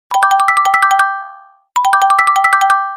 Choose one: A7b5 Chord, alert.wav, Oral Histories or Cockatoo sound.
alert.wav